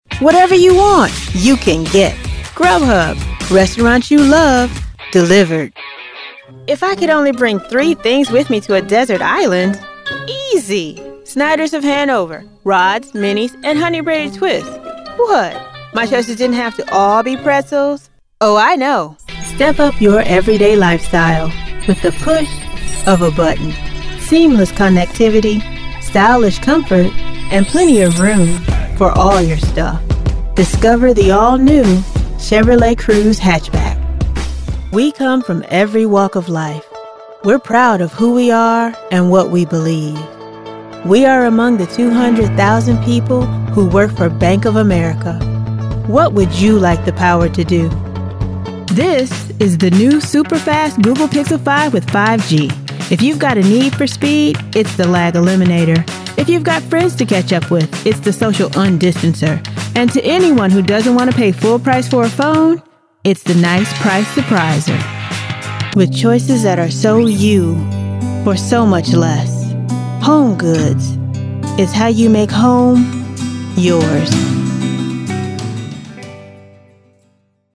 Commercial Demo
• 3 Zigma Chi Lollipop Microphone with shock mount
• Fully treated recording space
• Voice: Female Young Adult, Middle Age
• Accent: neutral American English